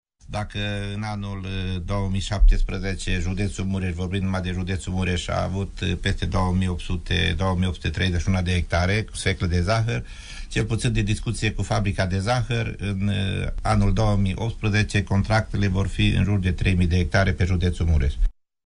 Cu toate acestea, cultivatorii mureşeni de sfeclă de zahăr nu ar trebui să fie îngrijoraţi, crede directorul Direcţiei pentru agricultură Mureş, Ioan Rus:
extras emisiunea Părerea Ta